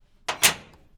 Part_Assembly_30.wav